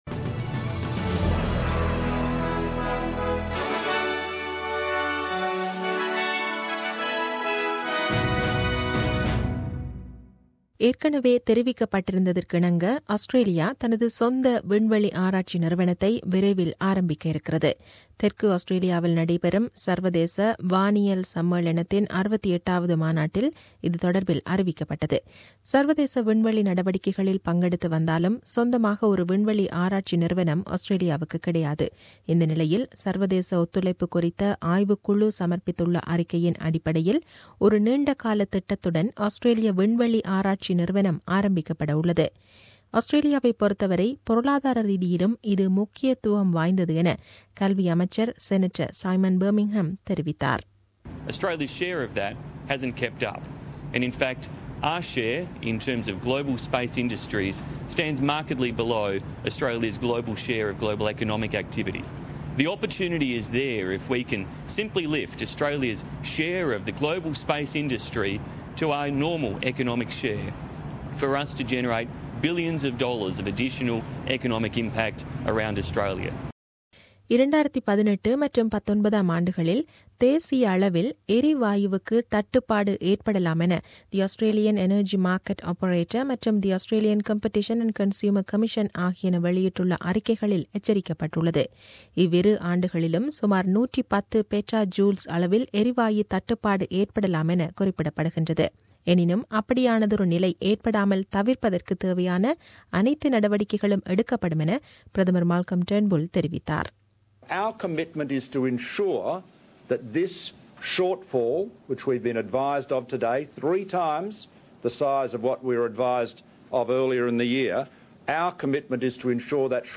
The news bulletin aired on 25 Sep 2017 at 8pm.